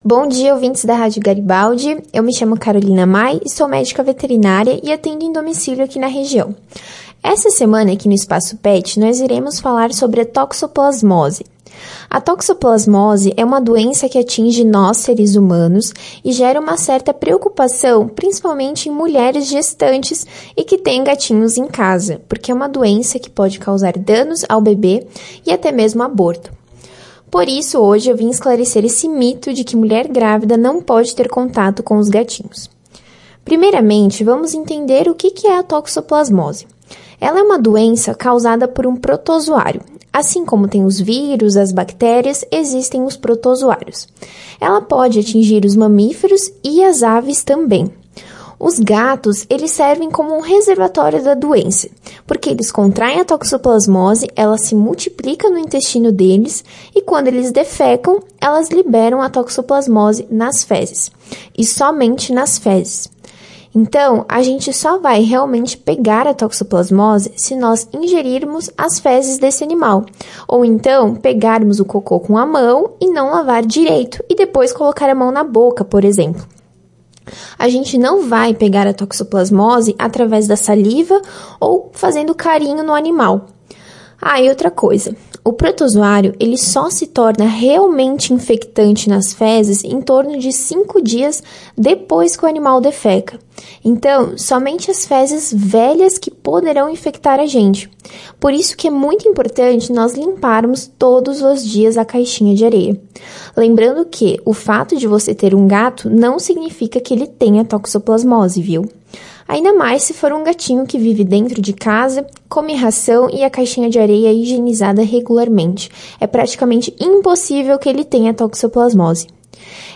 Doença foi abordada no comentário